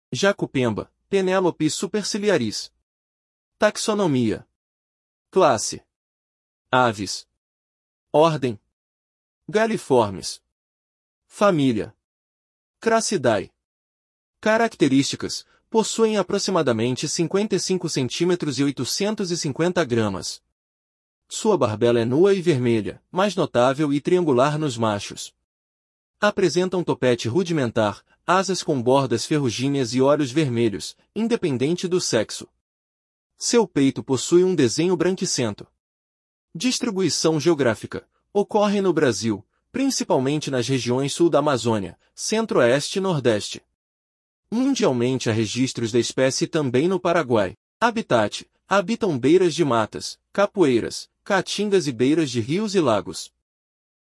Jacupemba (Penelope superciliaris)
Curiosidades: Vivem em bandos pequenos, de 3 a 5 indivíduos familiares. Sua vocalização é profunda e gutural.